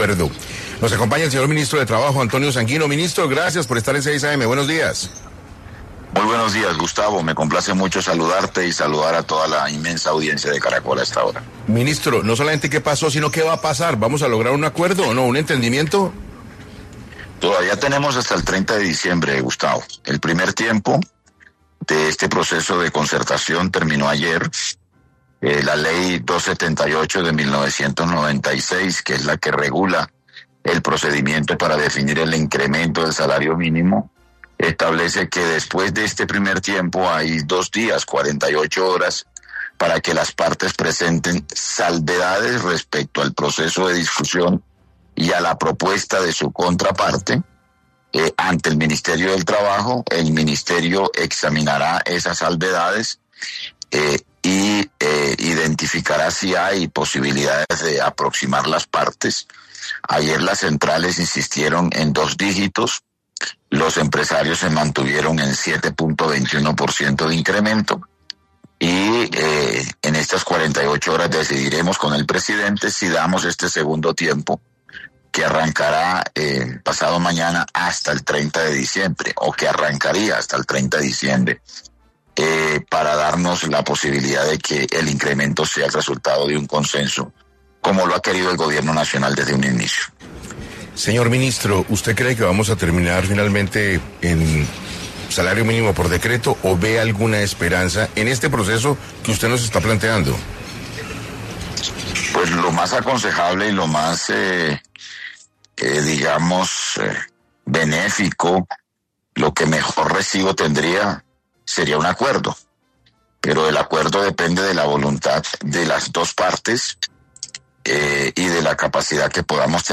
El ministro del Trabajo, Antonio Sanguino reveló en diálogo con 6AM de Caracol Radio de un nuevo esfuerzo para acercar posiciones entre las centrales obreras y los gremios empresariales.